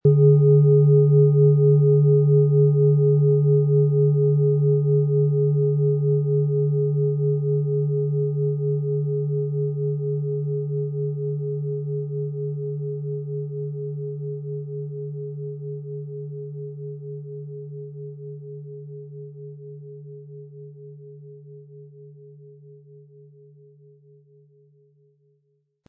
Es ist eine von Hand getriebene Klangschale, aus einer traditionellen Manufaktur.
• Tiefster Ton: Mars
Wir haben versucht den Ton so authentisch wie machbar hörbar zu machen, damit Sie hören können, wie die Klangschale bei Ihnen klingen wird.
PlanetentöneUranus & Mars
MaterialBronze